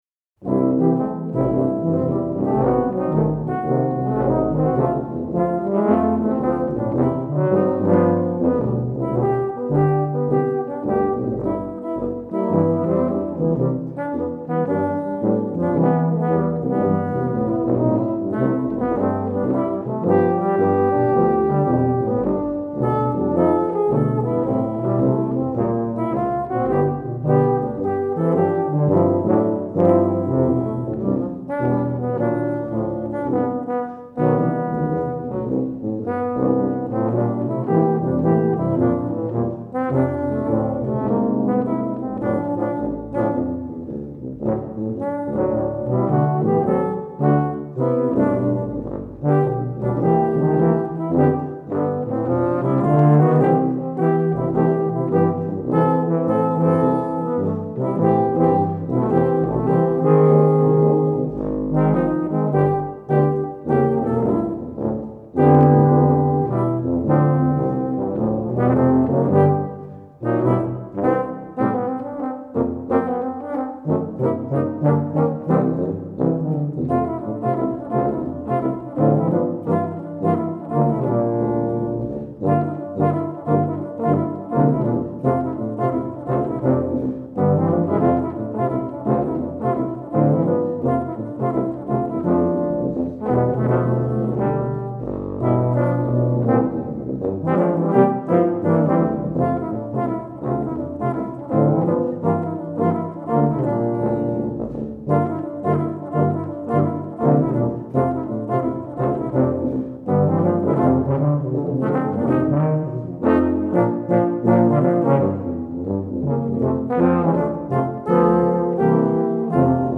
Voicing: Tuba / Euphonium Quartet